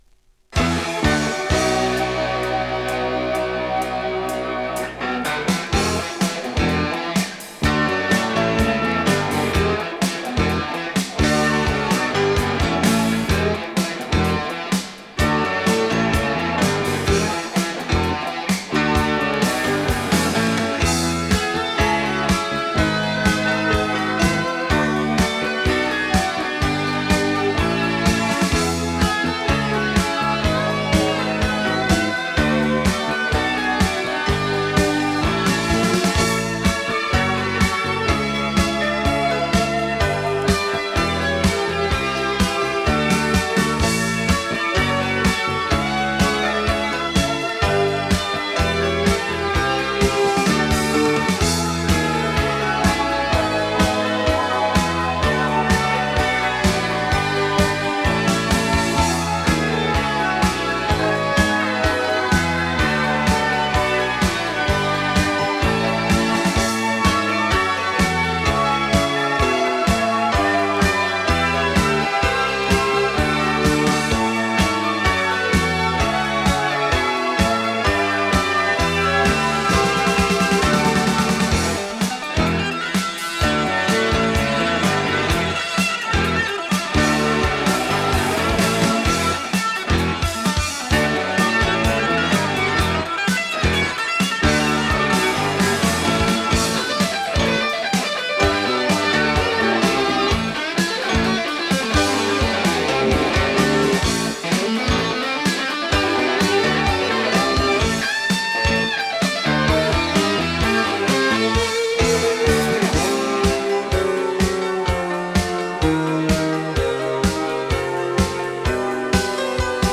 Äönitys tehtiin Subway/MSL studioilla.